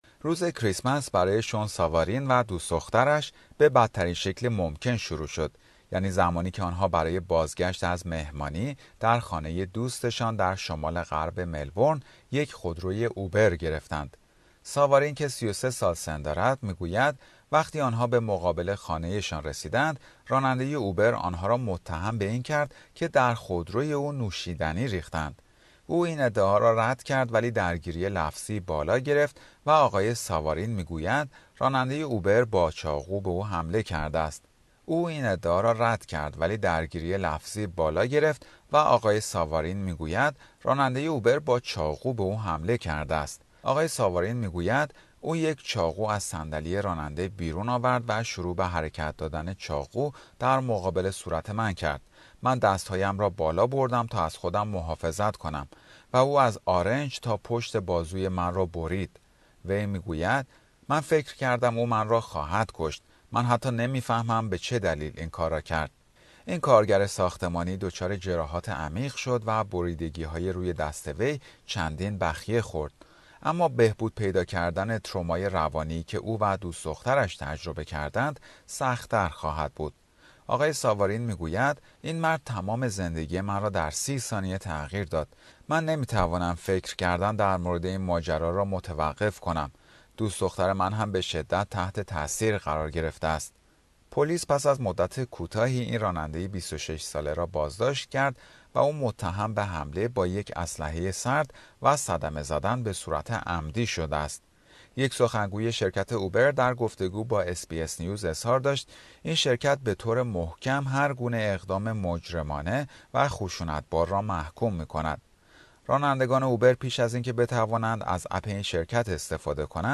توجه شما به شنیدن گزارشی در همین خصوص جلب می شود.